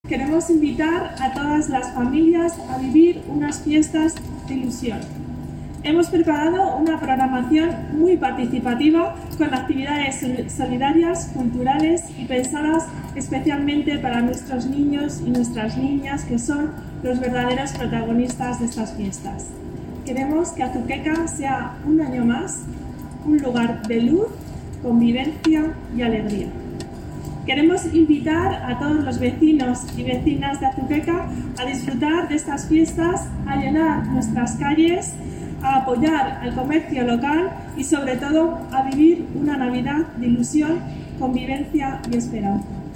Declaraciones de la alcaldesa en funciones sobre Navidad